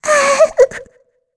Shea-Vox_Happy2_kr.wav